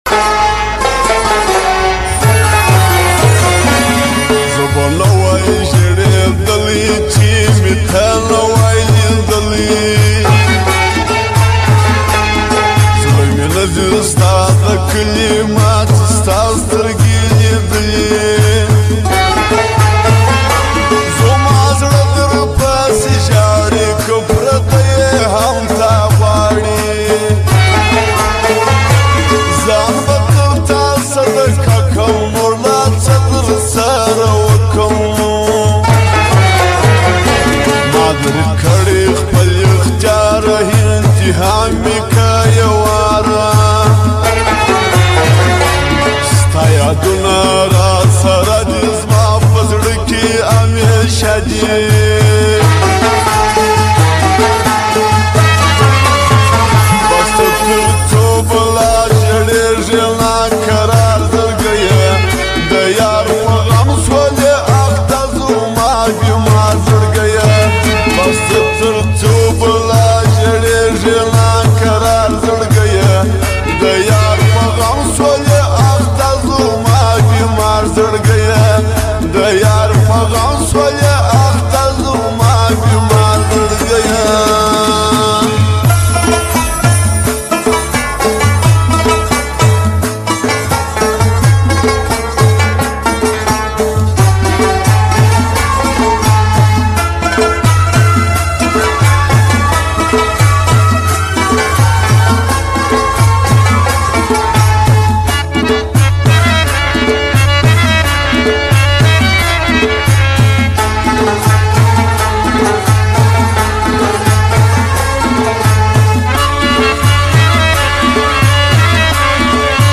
Pashto Sad Song